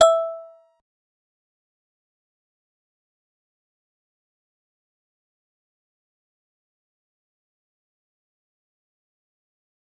G_Musicbox-E5-pp.wav